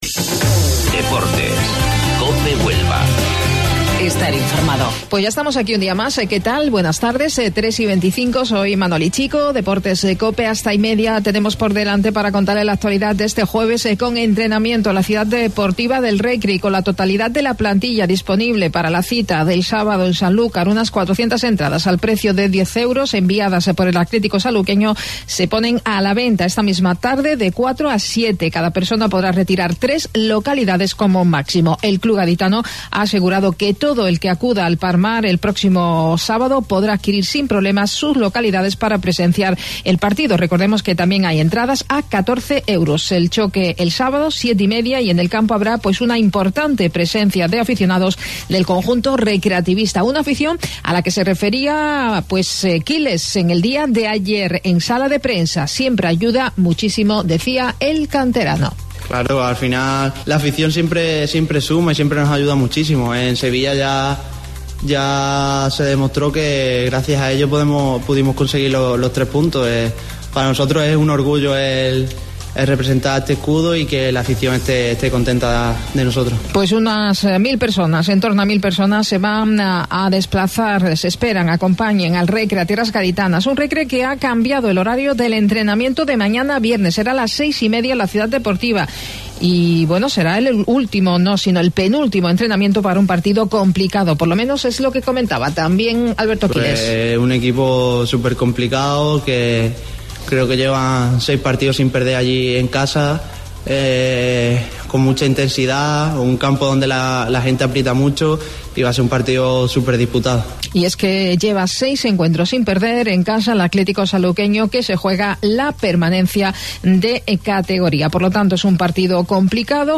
AUDIO: Informativo Local 14:20 del 2 de Mayo